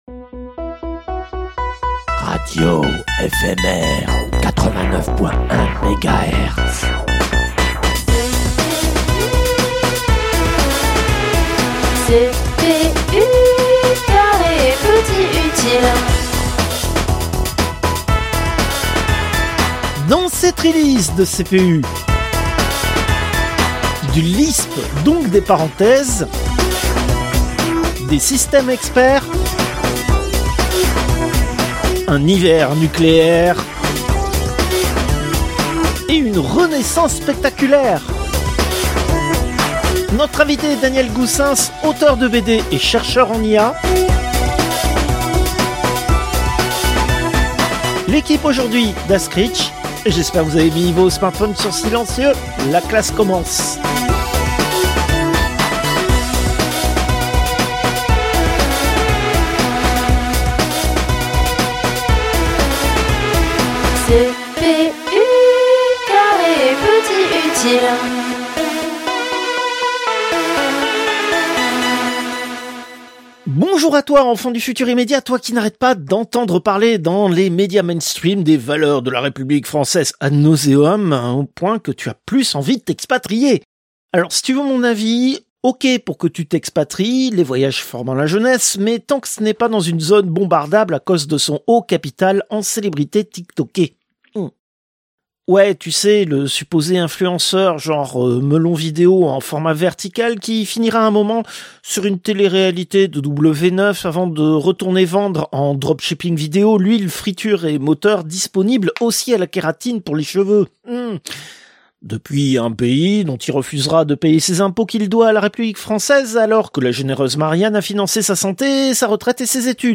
Dans cette release : Du Lisp donc des parenthèses, des systèmes experts, un hiver nucléaire, et une renaissance spectaculaire. Nous recevons Daniel Goossens, auteur de BD chez Fluide Glacial et chercheur en IA.